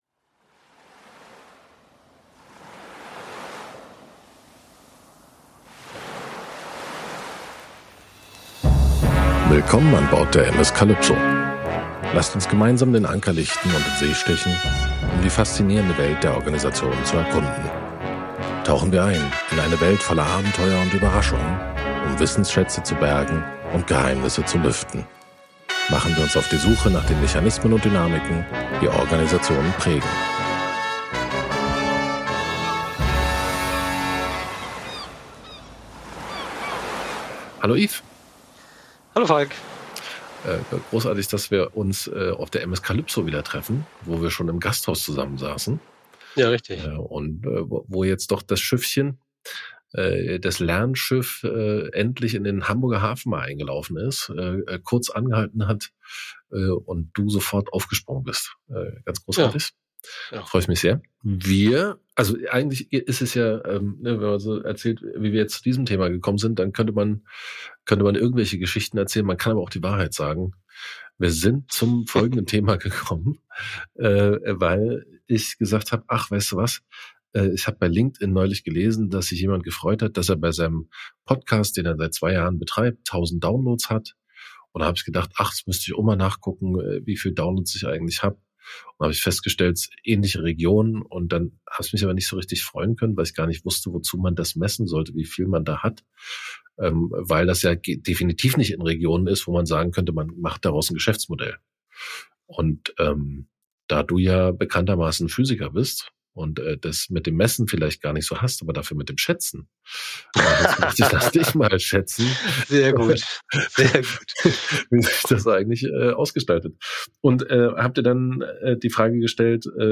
Ein faszinierender Dialog über die Kunst der Systembeobachtung durch Messungen - ohne in die Falle zu tappen, Zahlen für die Realität zu halten.